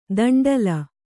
♪ danḍala